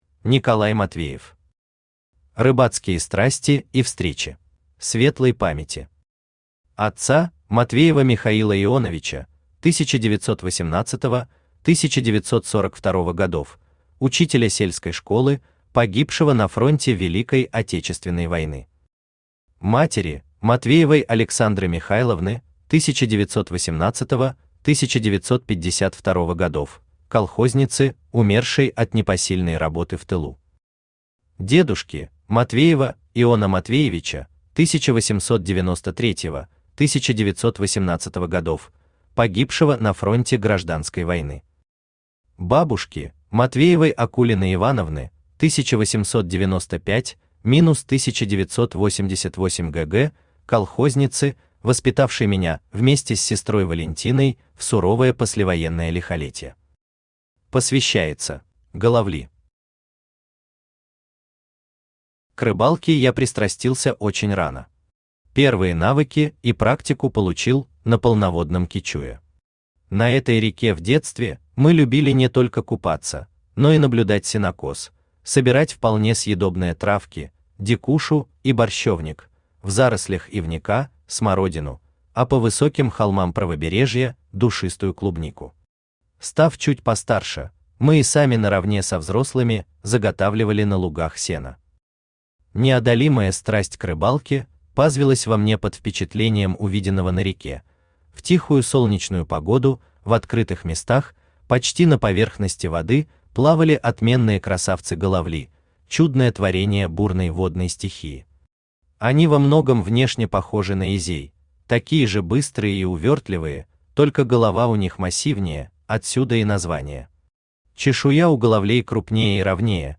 Аудиокнига Рыбацкие страсти и Встречи | Библиотека аудиокниг
Aудиокнига Рыбацкие страсти и Встречи Автор Николай Михайлович Матвеев Читает аудиокнигу Авточтец ЛитРес.